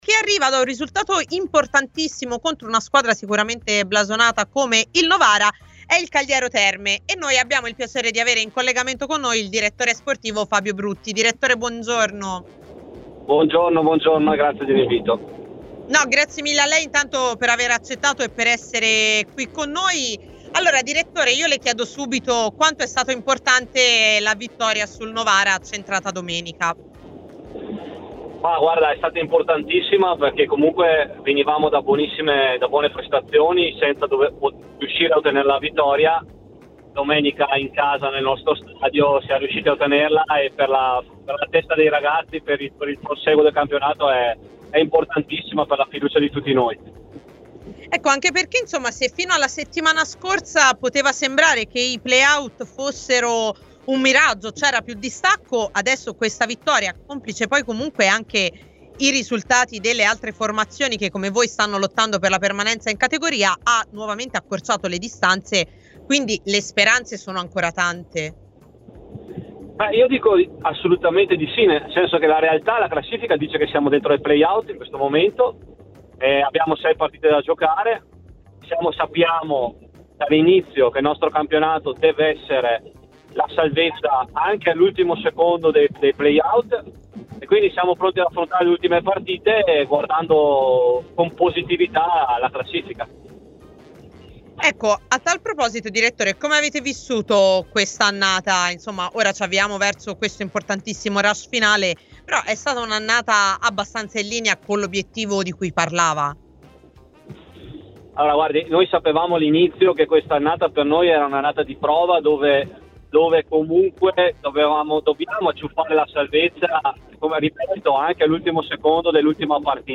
Nel corso della diretta mattutina di A Tutta C, format di TMW Radio interamente dedicato al mondo della Serie C